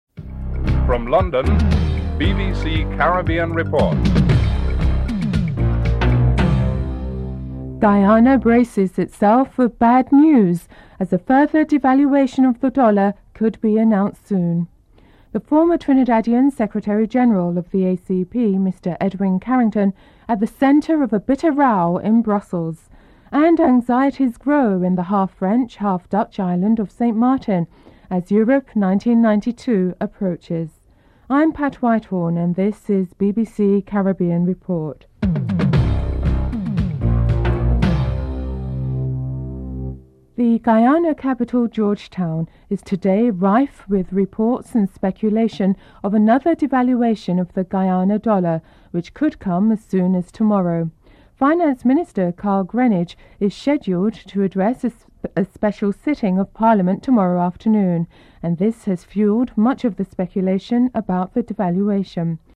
1. Headlines (00:00-00:39)
4. Financial News (05:50-06:44)